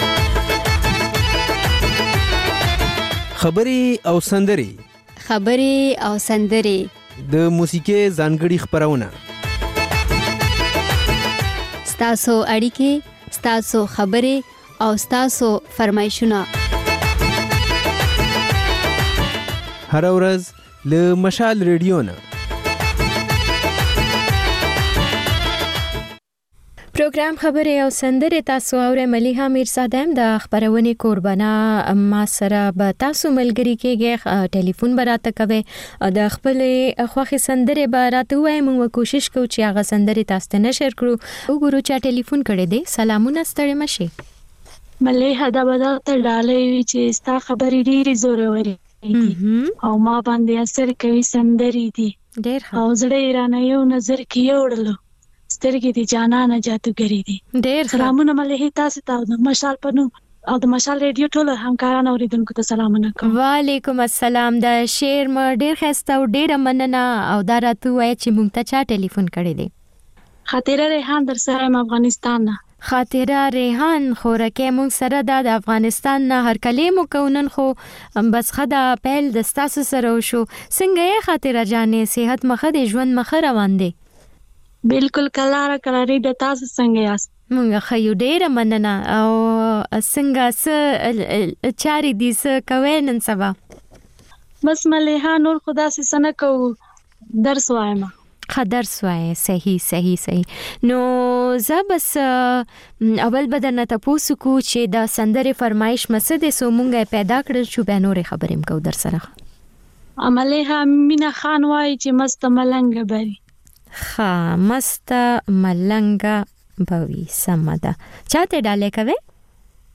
دلته د خبرې او سندرې خپرونې تکرار اورئ. په دې خپرونه کې له اورېدونکو سره خبرې کېږي، د هغوی پیغامونه خپرېږي او د هغوی د سندرو فرمایشونه پوره کېږي.